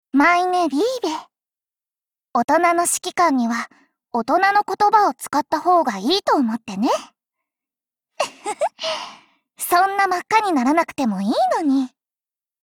贡献 ） 协议：Copyright，人物： 碧蓝航线:小欧根语音 您不可以覆盖此文件。